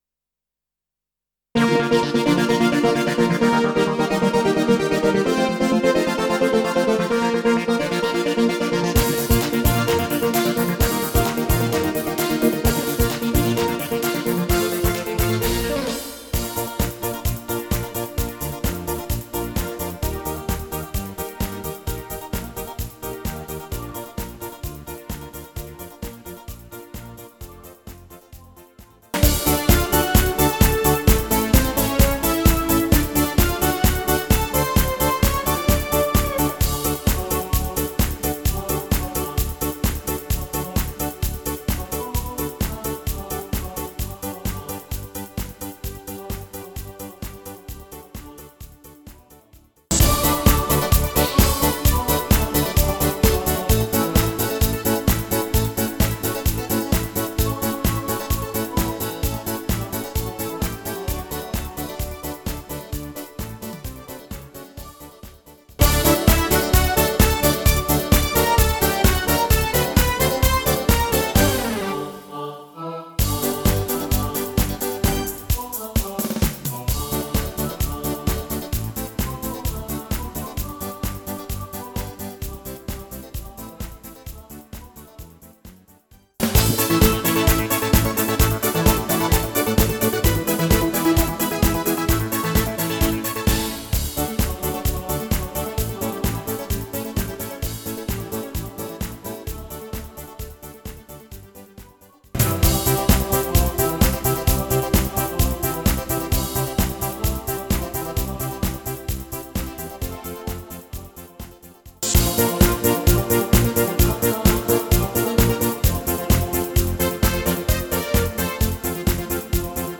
Rubrika: Pop, rock, beat
Karaoke
Rozšírené demo MP3 v prílohe.